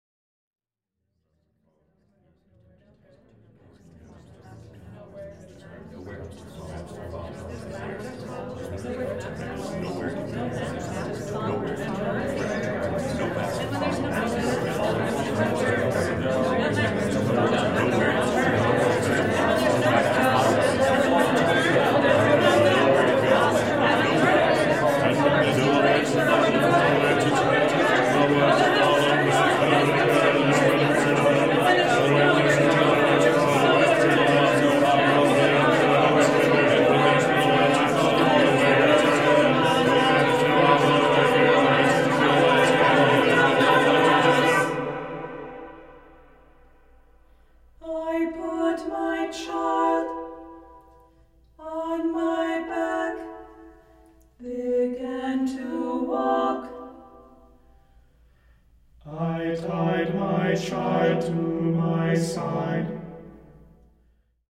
• Genres: Choral Music